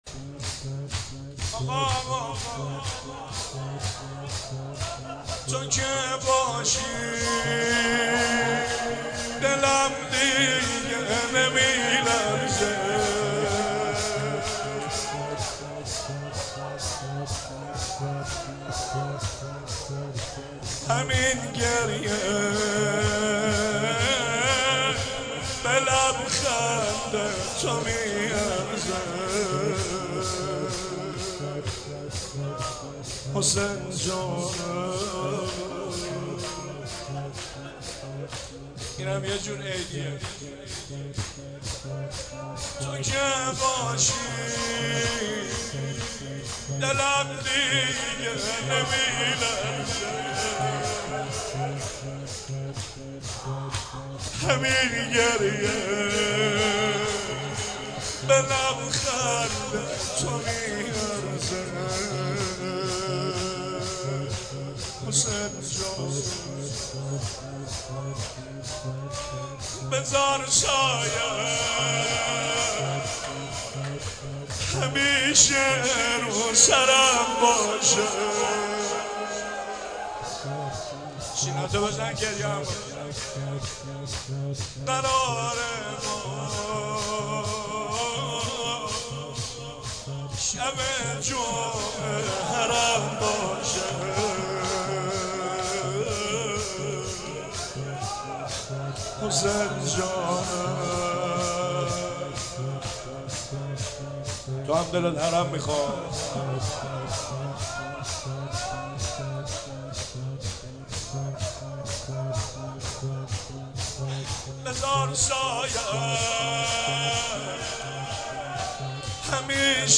چهاراه شهید شیرودی حسینیه حضرت زینب (سلام الله علیها)
شور